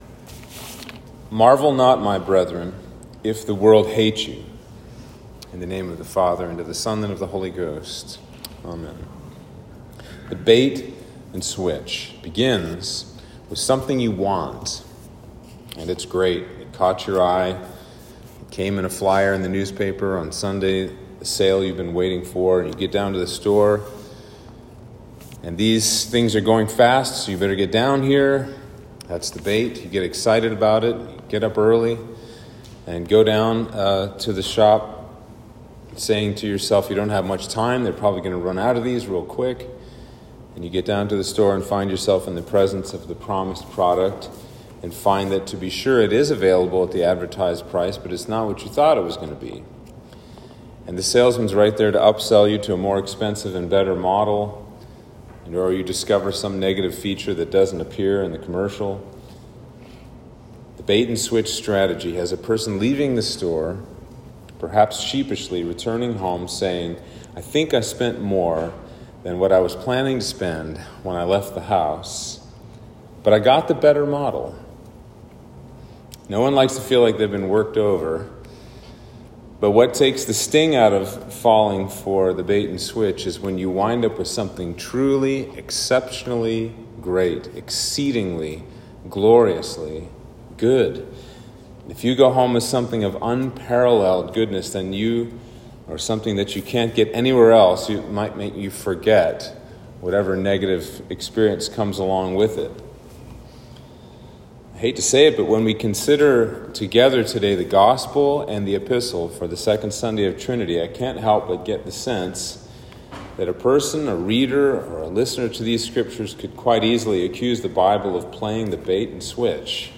Sermon for Trinity 2